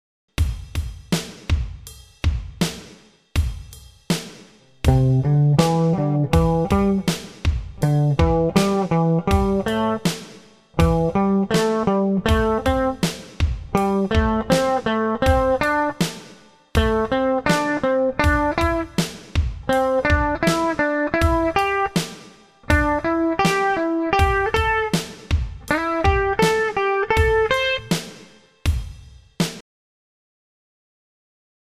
C Major pentatonic at the 8th fret.
C, D, E, G, A
Listen to this scale, it is played from the Low C note to the high C note, 8th fret. The audio file does not play the D note 10th fret high E string.